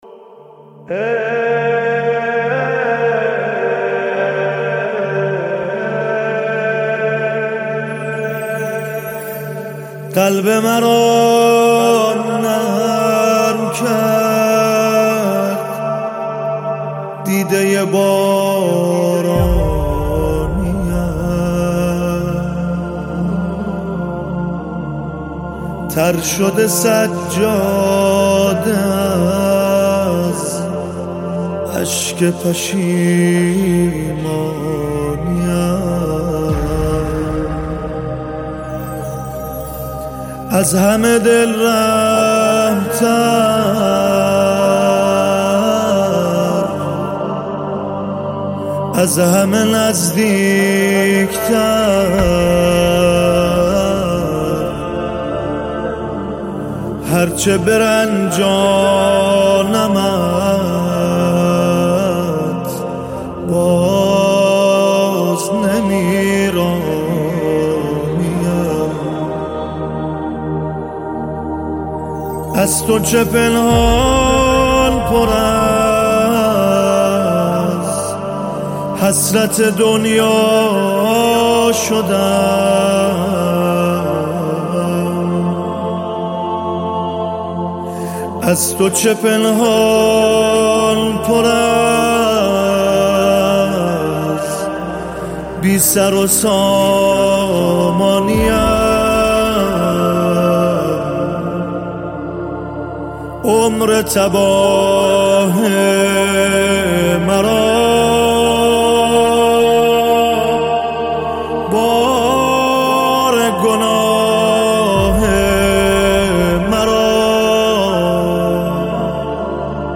اثری دل‌انگیز و معنوی ویژه ماه مبارک رمضان